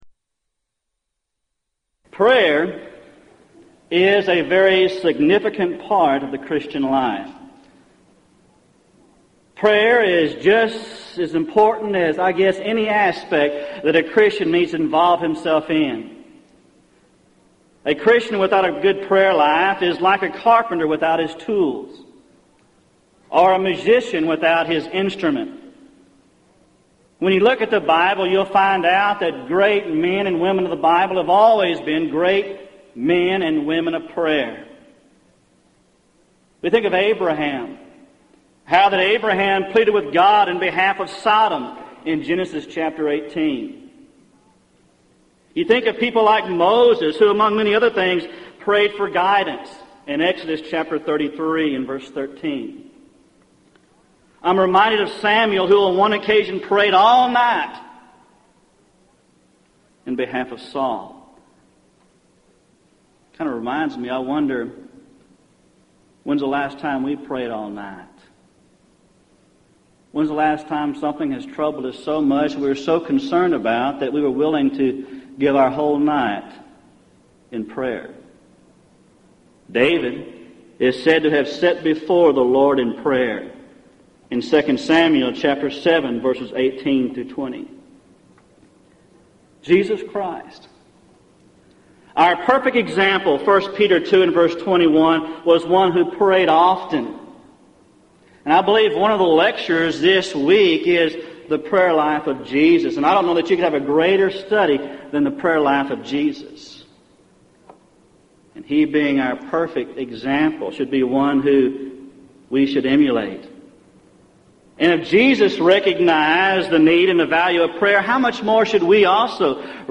Event: 1998 Gulf Coast Lectures Theme/Title: Prayer and Providence